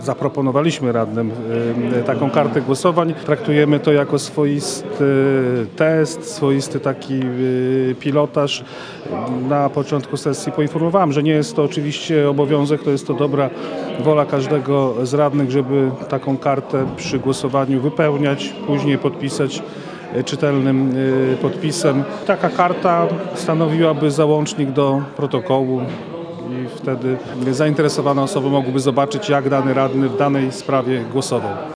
Taka forma była swego rodzaju testem przed wprowadzeniem nowych przepisów, zgodnie z którymi od nowej kadencji ujawnianie tego, jak głosowali radni, będzie obowiązkowe – mówi Dariusz Wasilewski, przewodniczący Rady Miasta w Ełku.